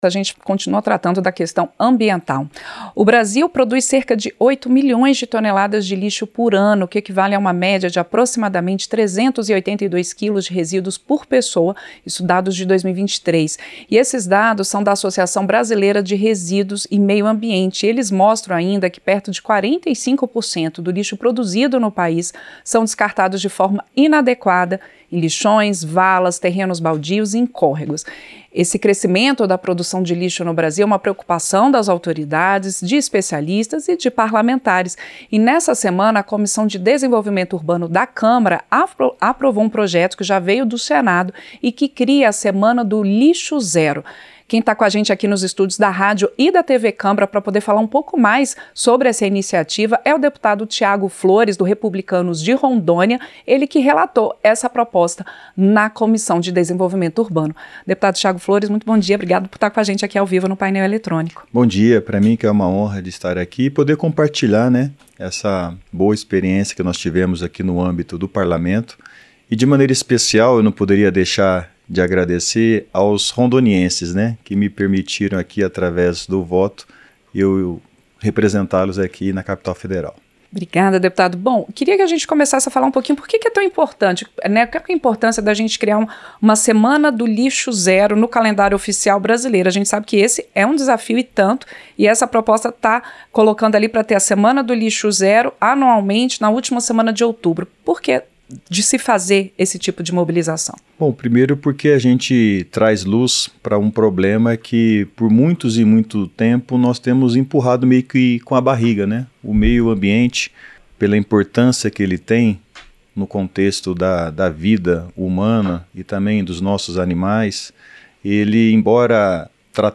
Entrevista - Dep. Thiago Flores (Republicanos-RO)